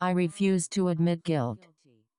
Improper Audio Downmix
AI services use naive downmixing (e.g., simple average) for multi-channel audio, while humans hear a standard-compliant mix, enabling A2A attacks.